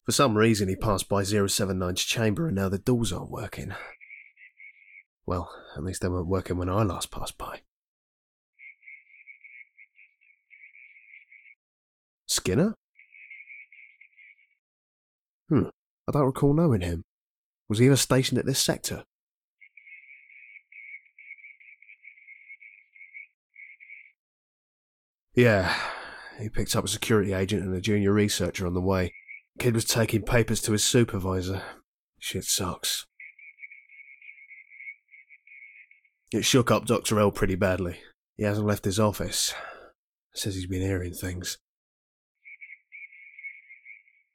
Conversation.ogg